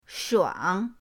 shuang3.mp3